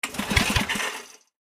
in_generator_pull_01_hpx
Electric generator starts and sputters then shuts off. Electric Generator Motor, Generator Engine, Generator